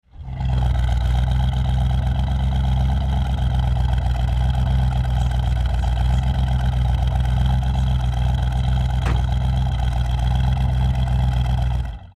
Klingeltöne
Thunderbird_sound2.mp3